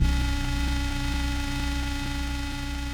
Pad - Static.wav